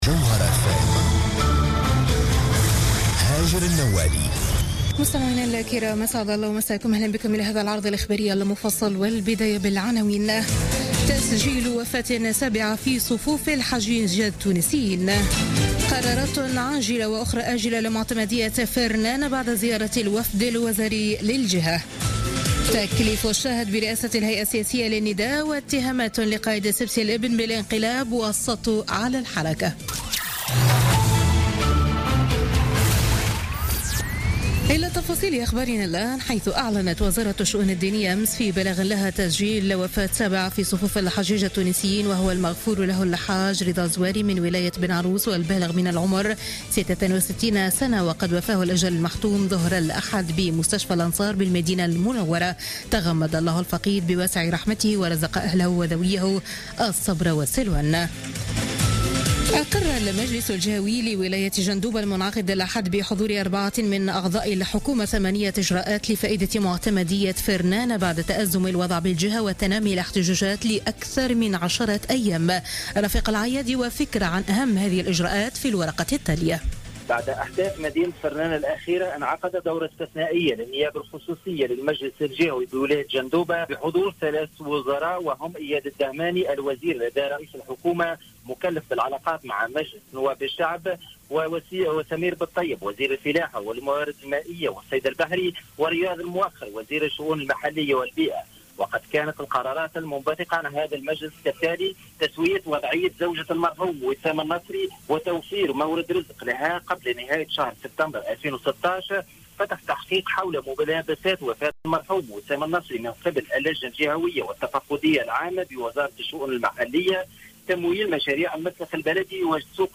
نشرة أخبار منتصف الليل ليوم الاثنين 19 سبتمبر 2016